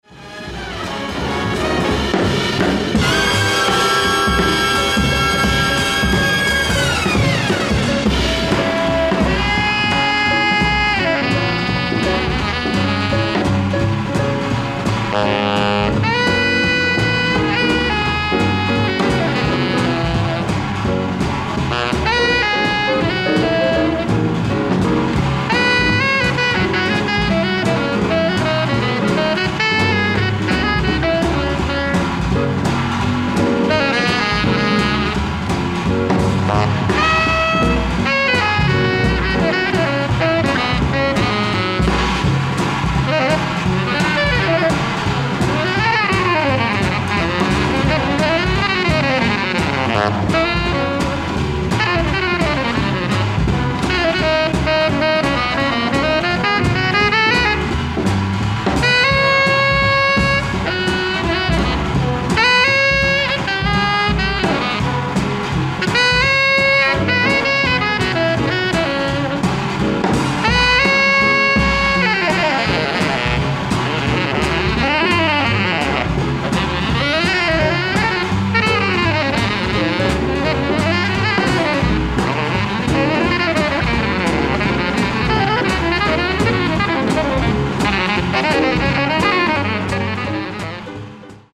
１－４：ライブ・アット・コングスベルグ・ジャズフェスティバル、ノルウェー 06/30/1974
※試聴用に実際より音質を落としています。